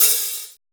HIT OHH.wav